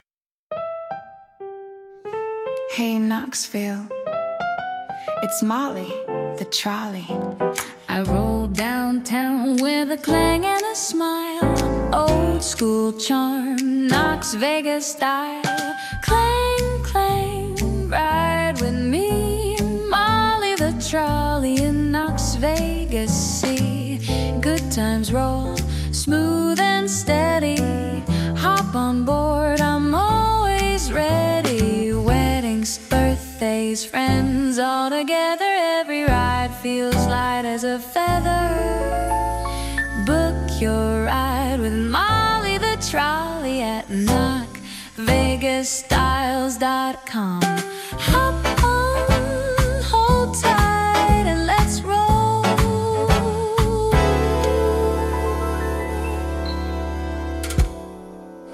Radio Commercial for Molly the Trolley
The project blended old-school charm with modern commercial pacing to create multiple finished audio spots tailored for social media, website use, radio, and live events. By positioning Molly as a recognizable brand voice and prioritizing a web-first call to action, the campaign gave Knox Vegas Style a flexible, memorable audio identity designed to drive bookings and support long-term brand recognition across corporate events, celebrations, and city experiences.